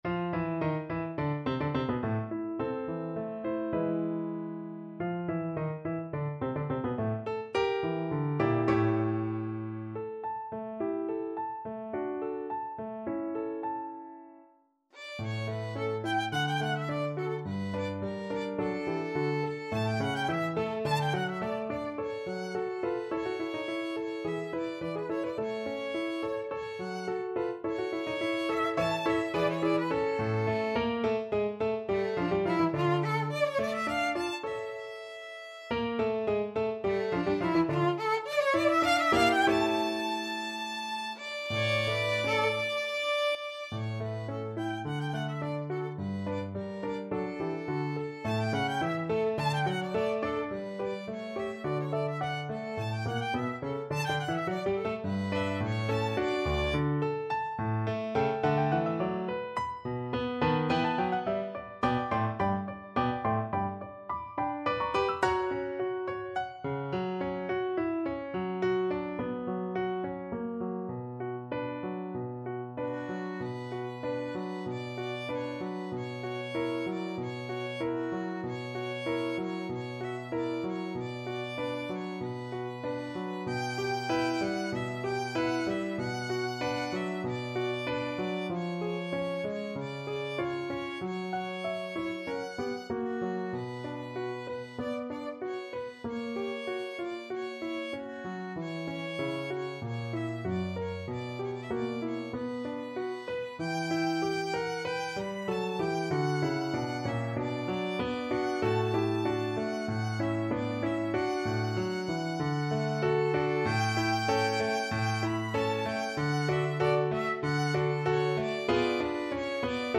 Allegretto =106
2/4 (View more 2/4 Music)
G4-B6
Classical (View more Classical Violin Music)